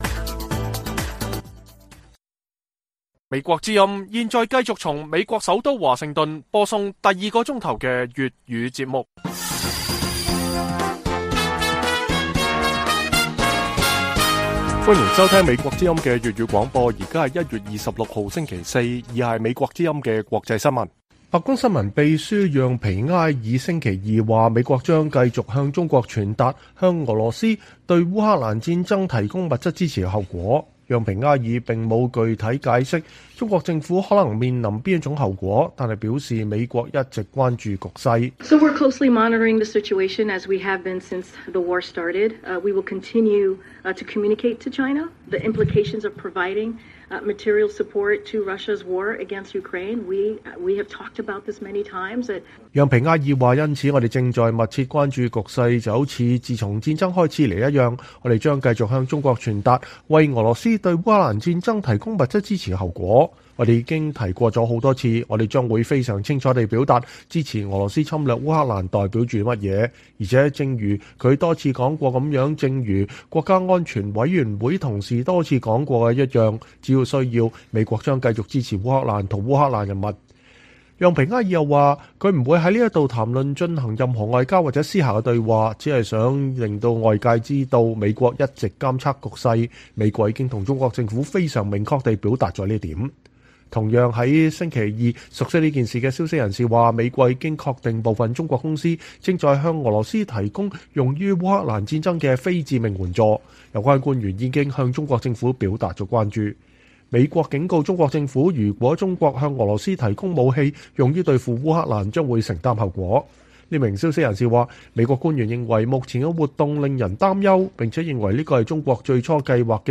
粵語新聞 晚上10-11點:白宮說美國將向中國傳達幫助俄羅斯的後果